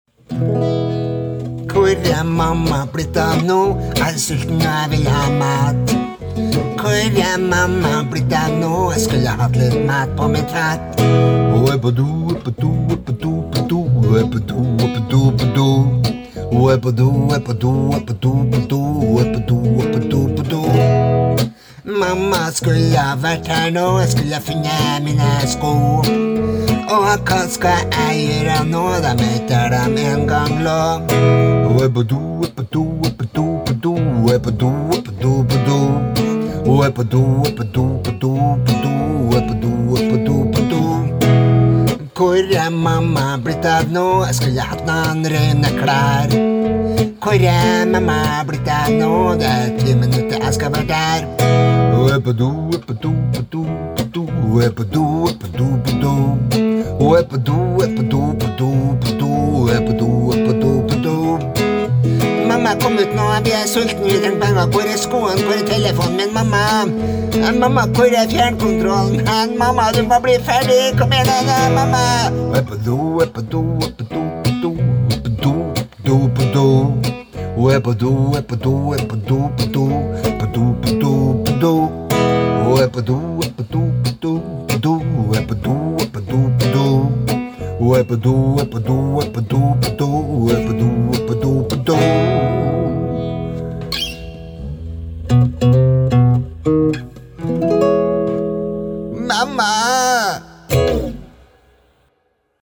Mandag 27. desember 2021: HO E PÅ DO – BARNESANG (Sang nr 117 – på 117 dager)
Spilt inn med telefon …